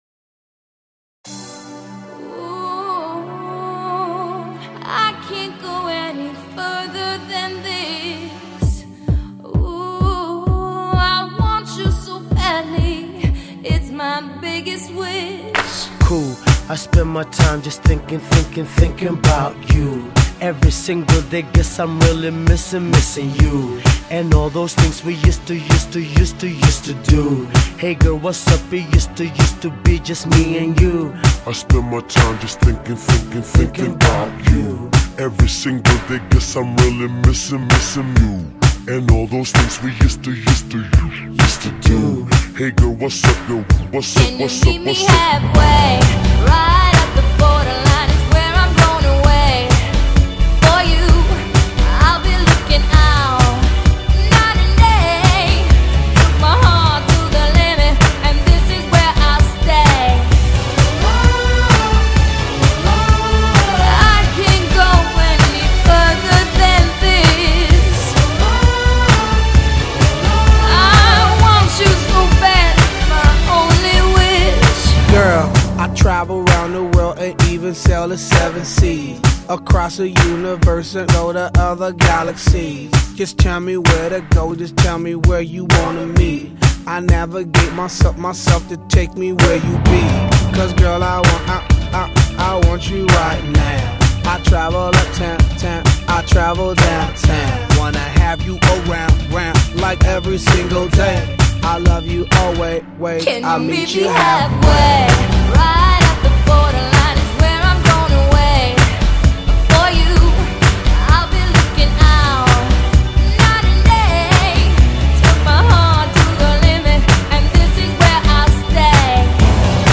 Genre: R&B